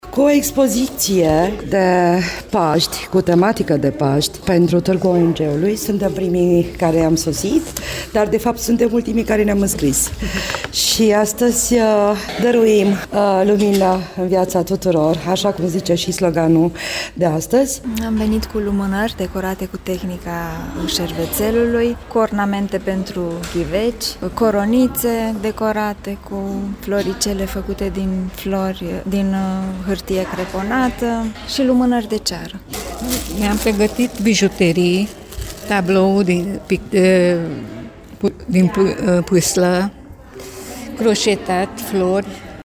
Expozanţii s-au pregătit cu produse realizate cu grijă de beneficiarii serviciilor organizaţiilor pe care le reprezintă: